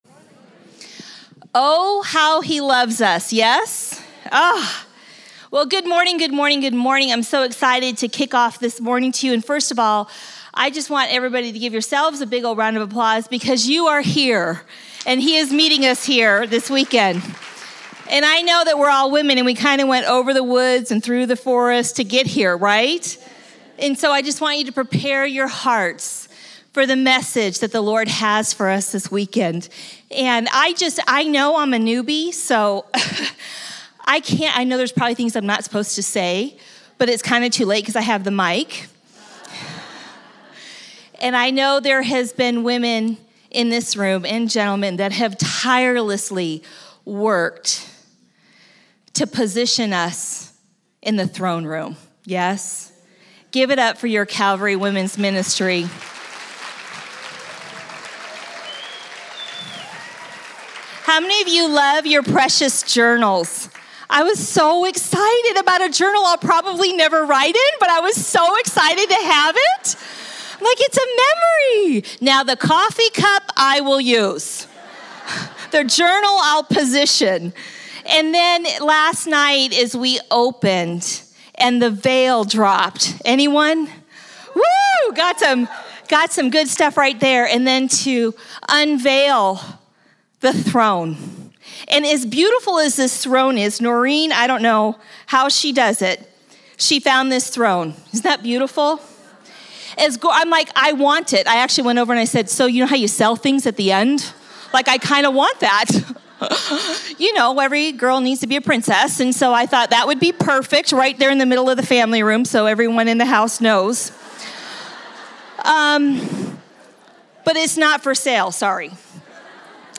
2018 Women's Retreat: Pray, Come Boldly Before His Throne.
Women's Retreat 2018